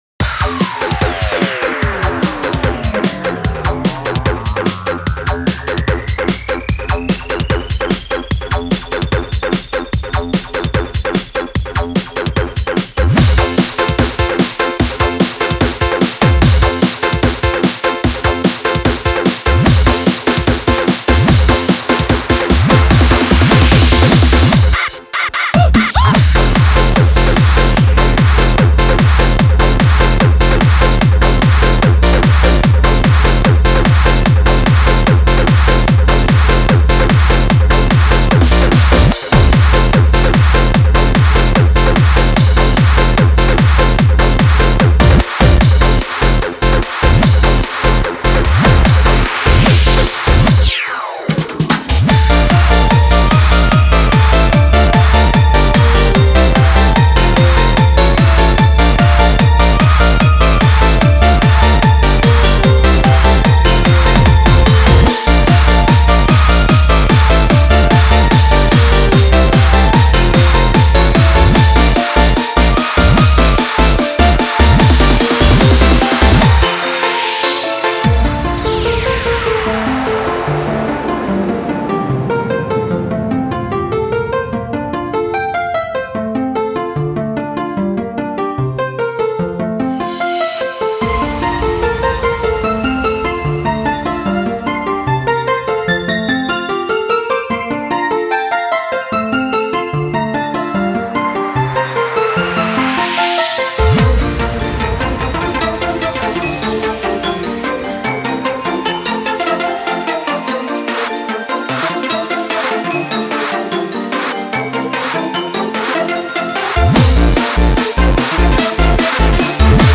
J-Core